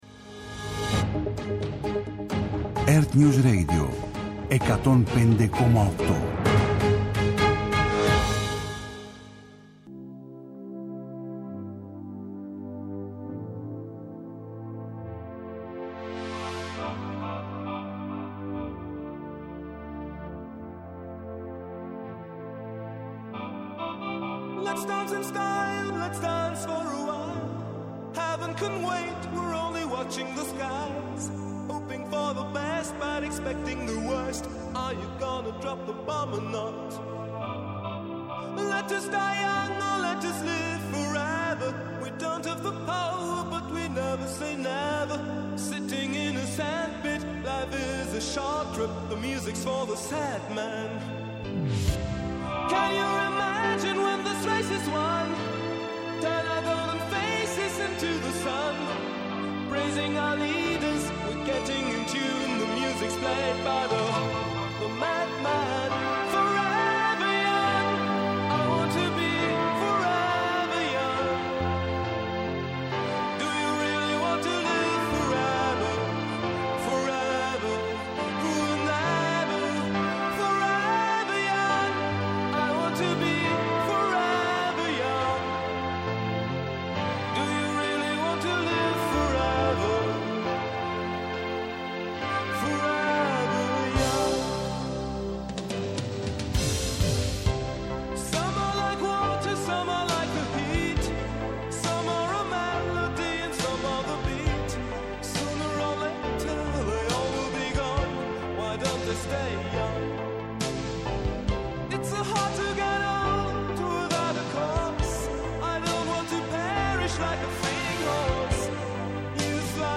Νυχτερινές ιστορίες με μουσικές και τραγούδια που έγραψαν ιστορία.